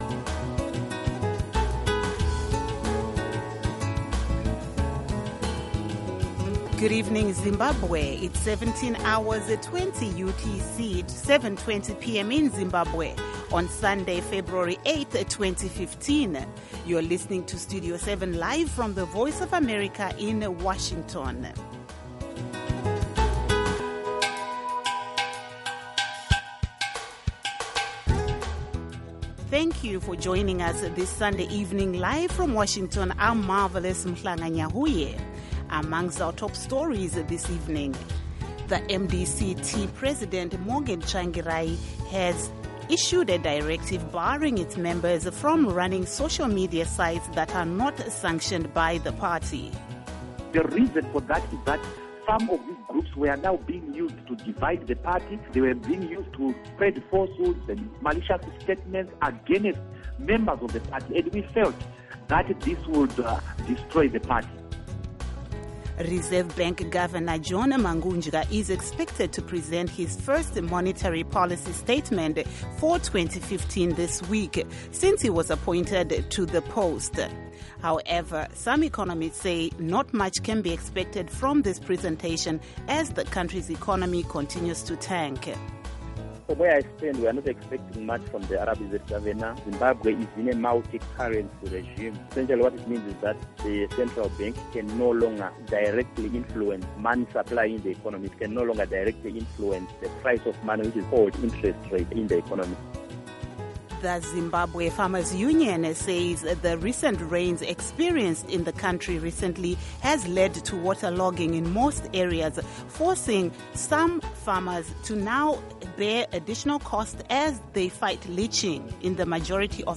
Studio 7 has been providing Zimbabwe with objective, reliable and balanced radio news broadcasts since 2003 and has become a highly valued alternative point of reference on the airwaves for many thousands of Zimbabweans. Studio 7 covers politics, civil society, the economy, health, sports, music, the arts and other aspects of life in Zimbabwe.